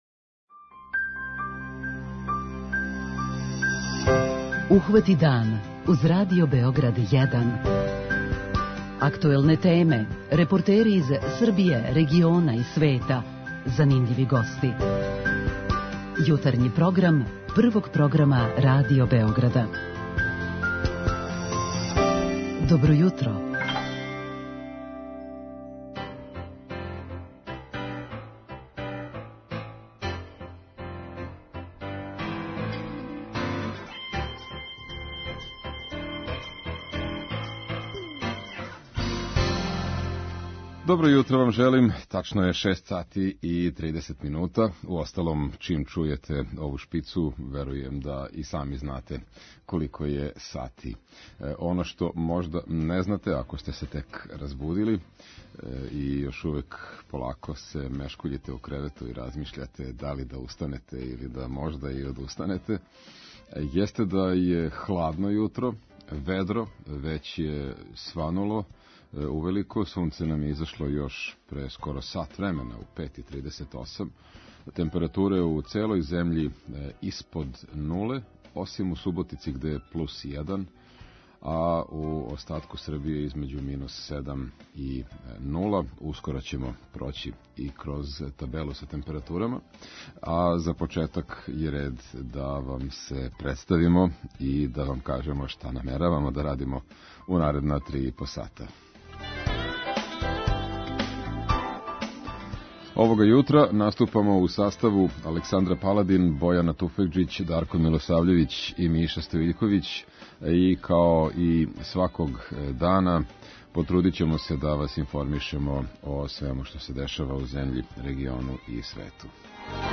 Група аутора Јутарњи програм Радио Београда 1!